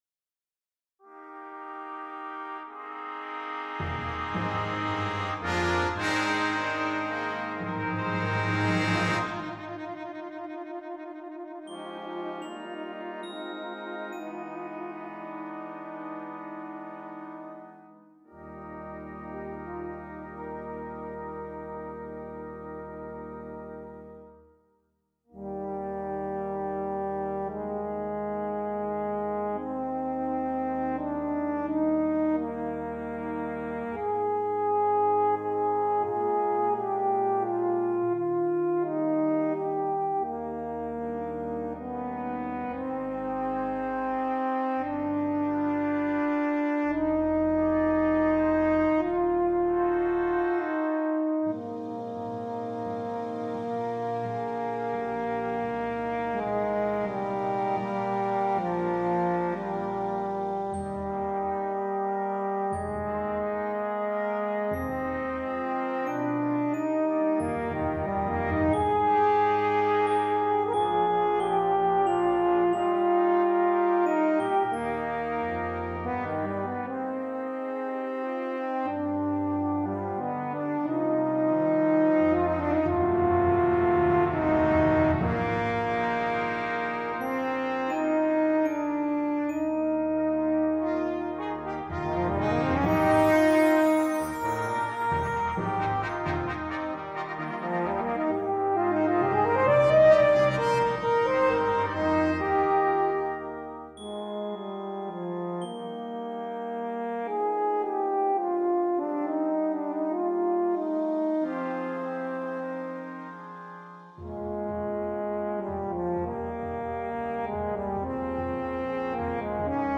lullaby for horn and piano